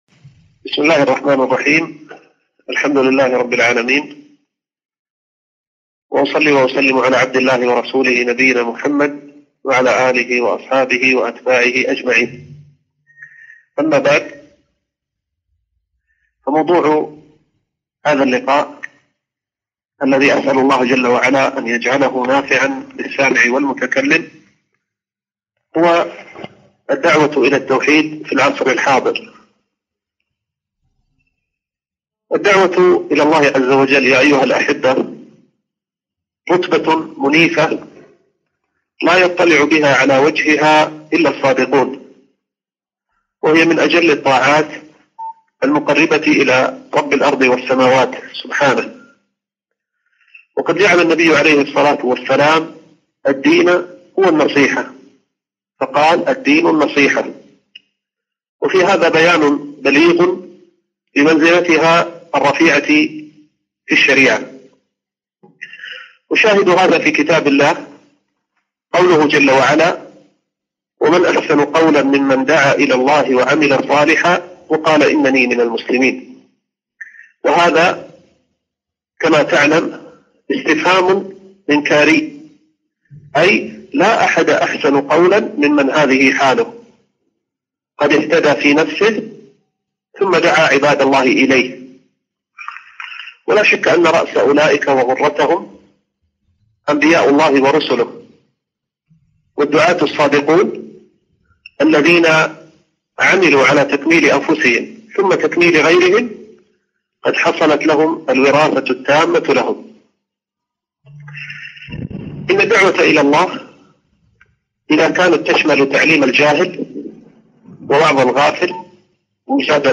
محاضرة - الدعوة إلى التوحيد في العصر الحاضر